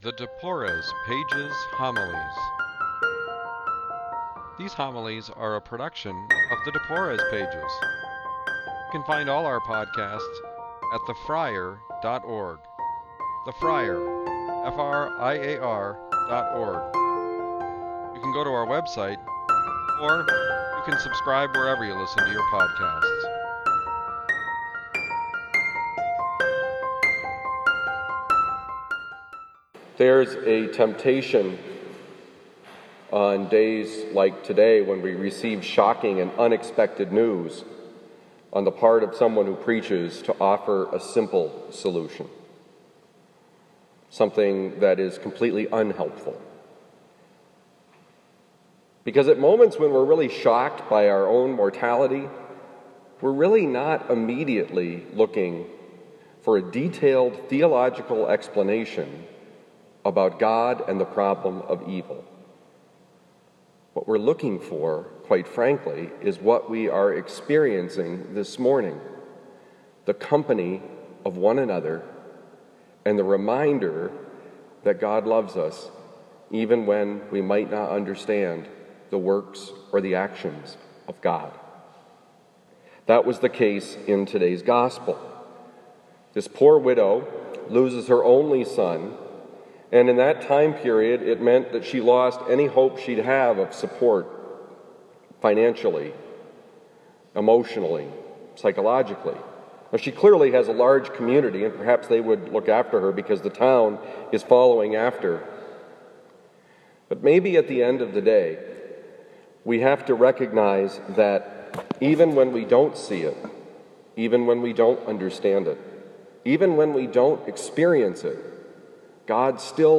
Homily for the 24th Tuesday in Ordinary Time, given at Christian Brothers College High School on September 17, 2019.